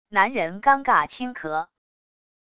男人尴尬轻咳wav音频